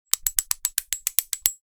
Rotating Sprinkler Mechanical, Garden, Outdoor 2 Sound Effect Download | Gfx Sounds
Rotating-sprinkler-mechanical-garden-outdoor-2.mp3